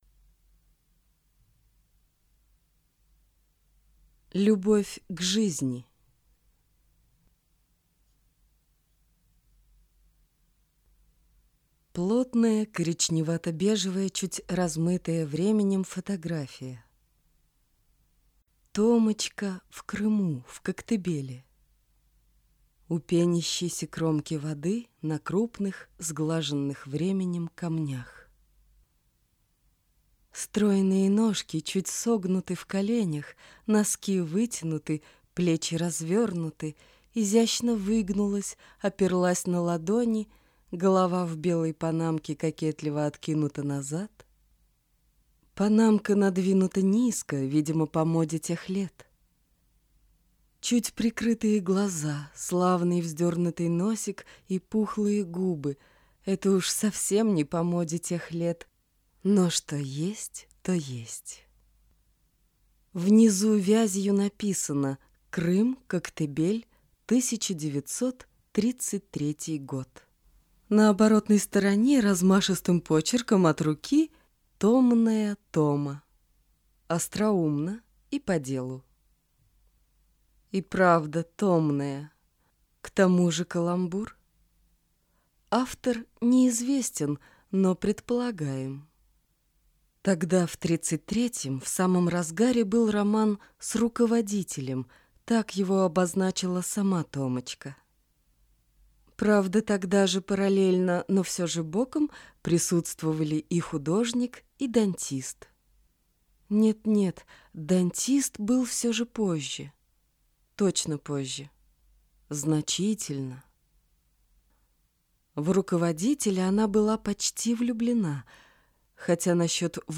Аудиокнига Любовь к жизни | Библиотека аудиокниг